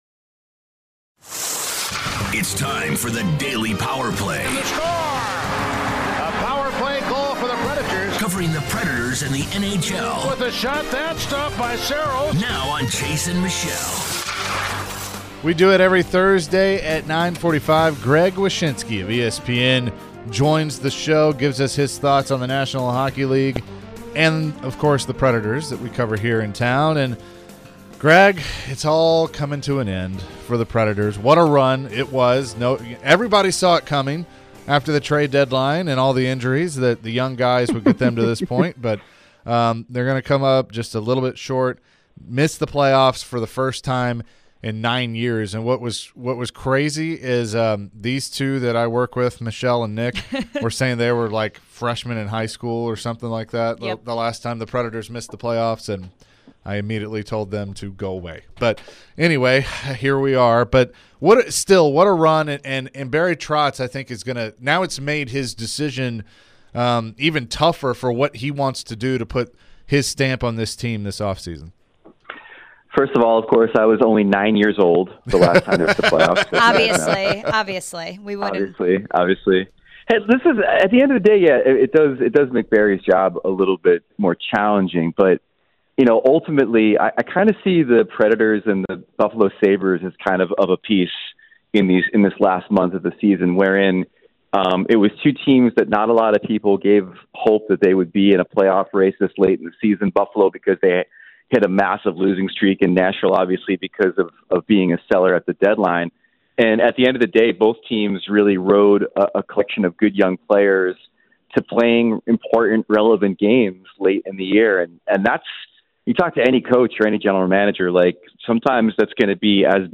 Greg Wyshynski Interview (4-13-23)
NHL Senior Writer Greg Wyshynski joins for his weekly visit. He weighed in on Barry Trotz & the outlook for the NHL Playoffs.